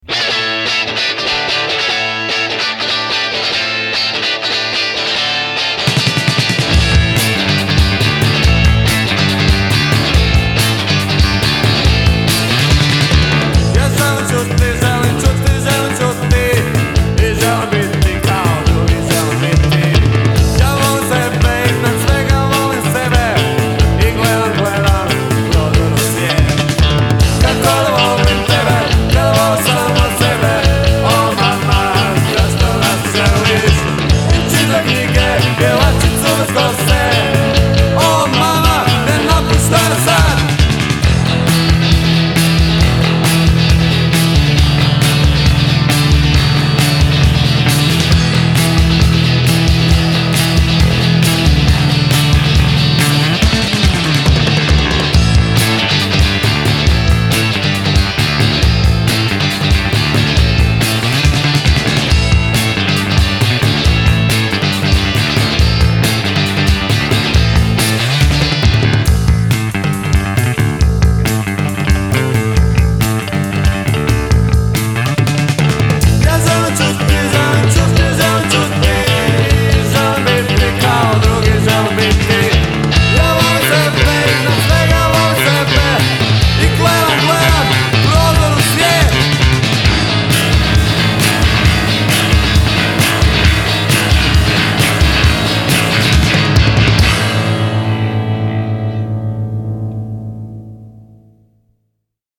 bass
guitar and voice
drums
recorded in a very bad studio